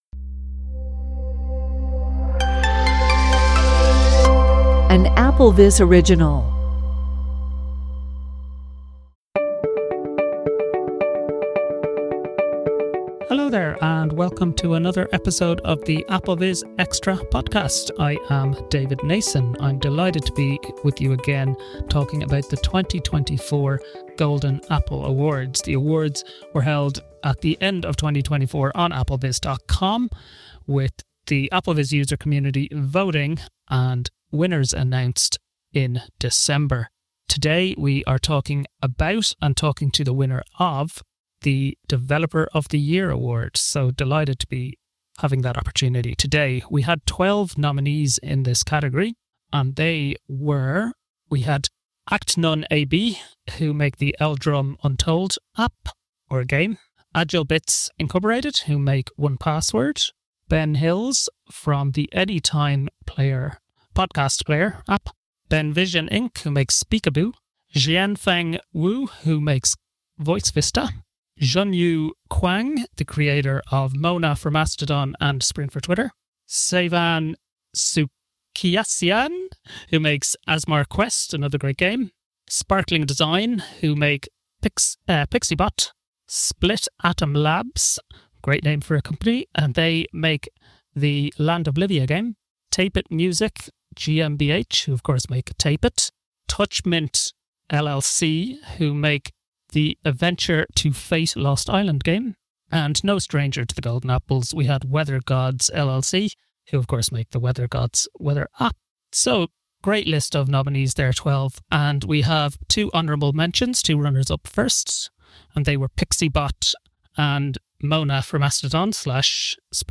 In conversation with the creators of Voice Vista, Winner of Best Developer in the 2024 Golden Apples | AppleVis